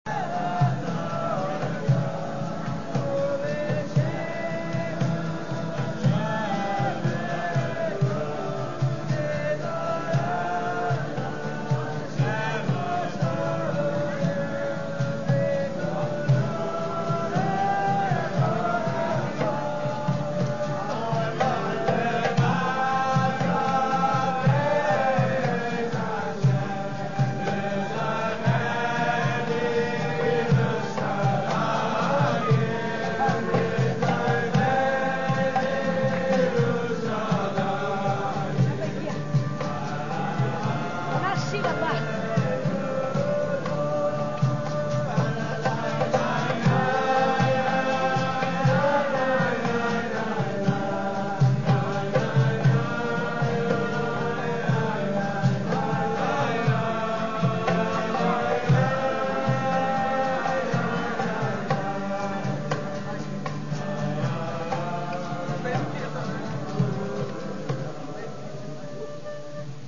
When I started tape recording the singing a Jewish lady asked my wife what I was doing.
The lady introduced her husband and grandchildren and pointed out her son, playing the guitar.
Her husband took me into the circle of Jewish men dancing around the musicians.